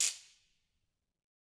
Ratchet1-Crank_v1_rr1_Sum.wav